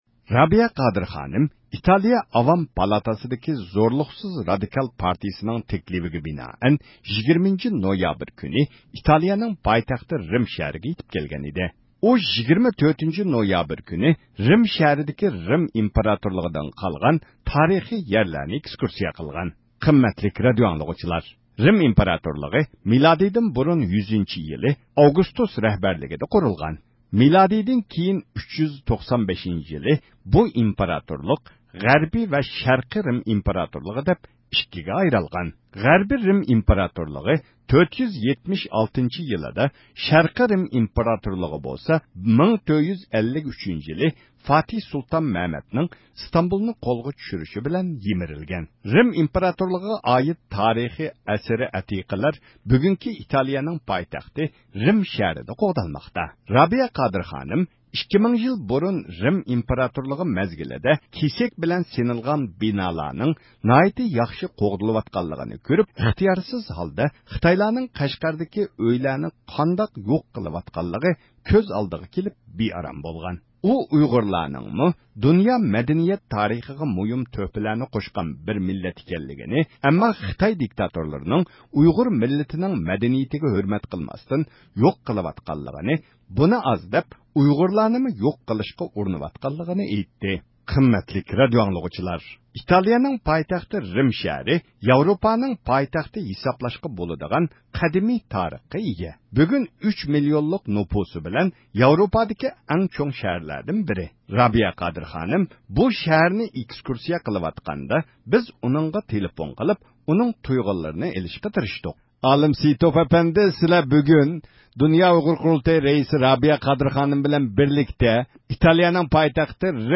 رابىيە قادىر خانىم بۇ شەھەرنى ئېكسكۇرسىيە قىلىپ، ئۆز تەسىراتلىرىنى ئوتتۇرىغا قويدى.